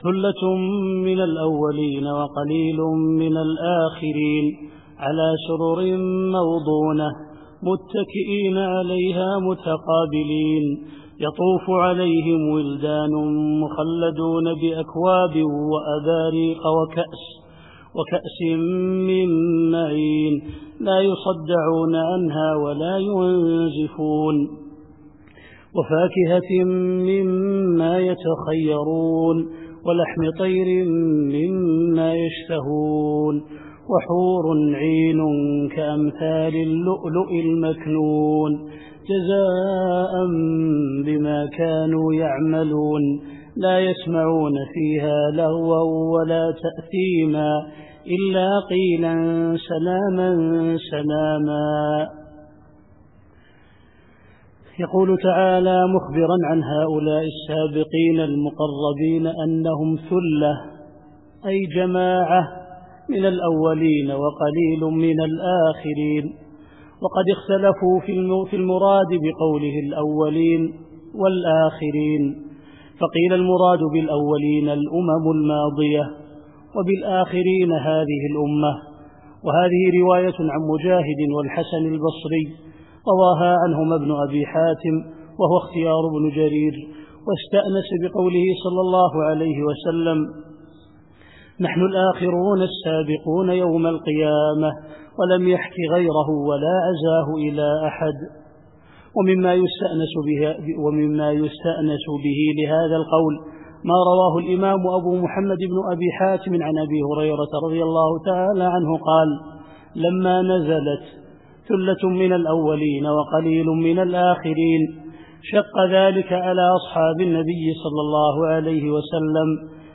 التفسير الصوتي [الواقعة / 13]